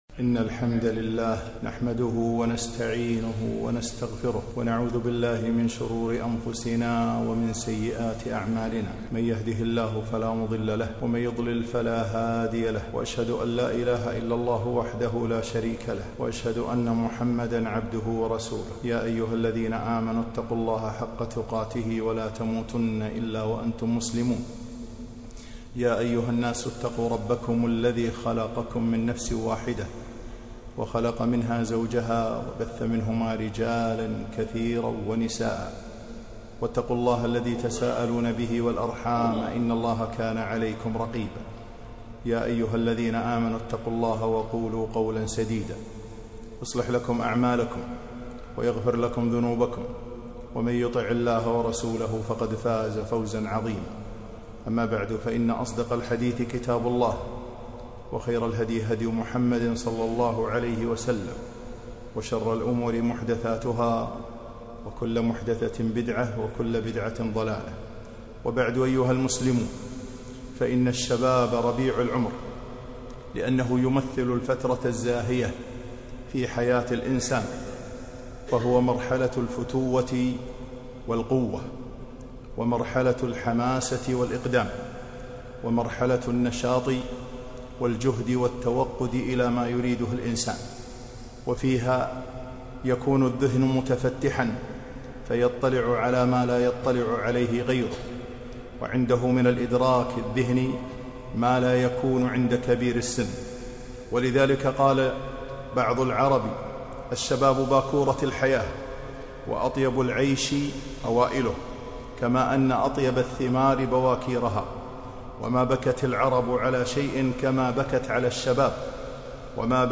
خطبة - الشباب ربيع العمر